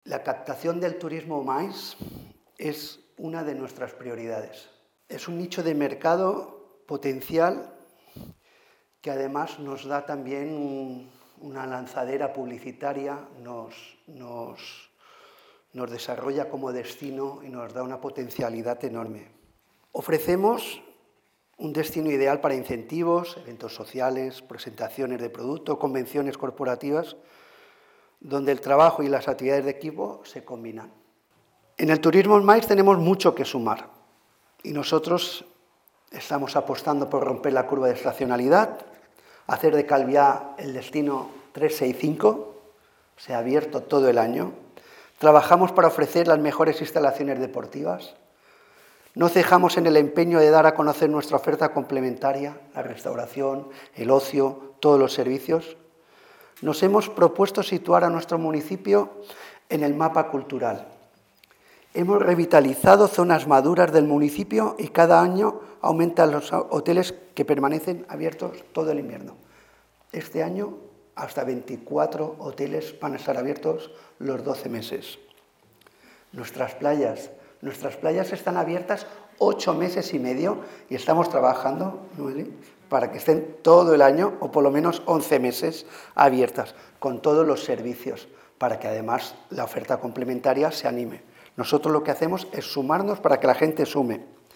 declaraciones-alcalde.mp3